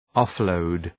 Shkrimi fonetik {,ɒf’ləʋd}